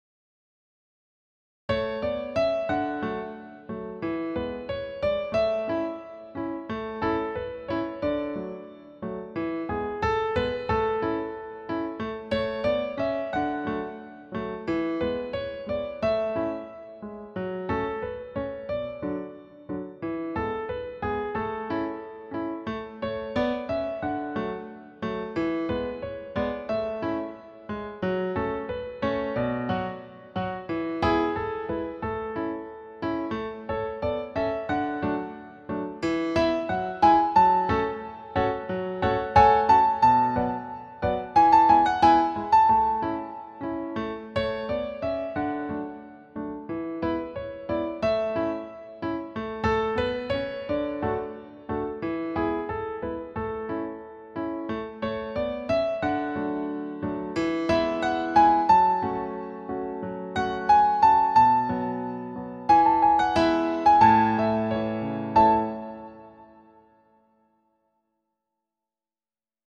Für Klavier Solo
Jazz/Improvisierte Musik
Neue Musik
Klavier (1)